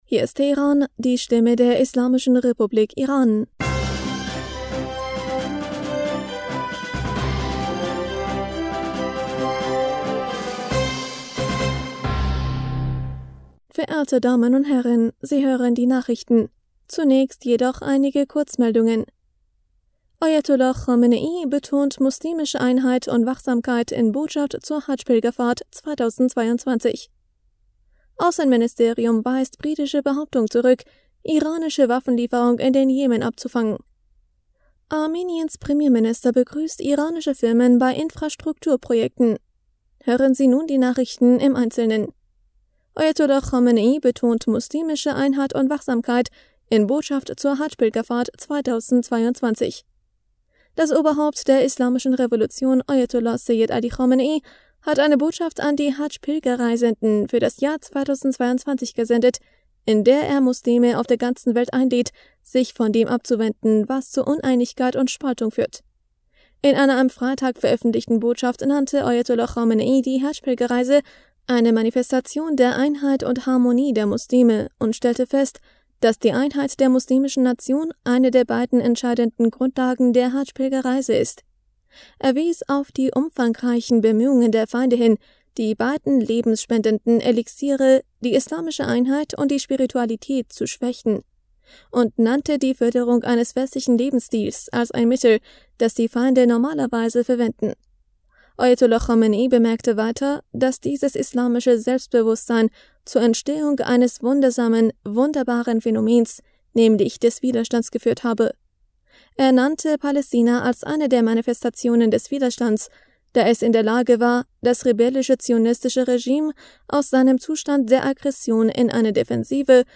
Nachrichten vom 8. Juli 2022